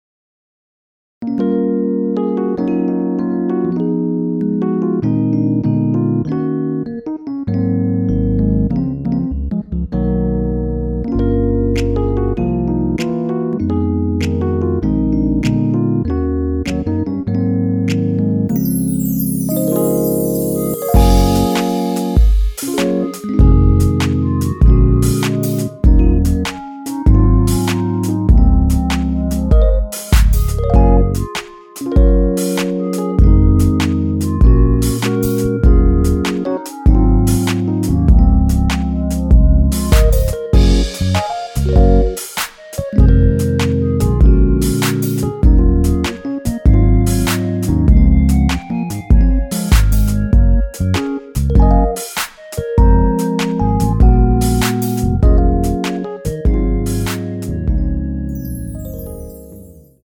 원키에서(-3)내린 멜로디 포함된 MR입니다.
Db
앞부분30초, 뒷부분30초씩 편집해서 올려 드리고 있습니다.
중간에 음이 끈어지고 다시 나오는 이유는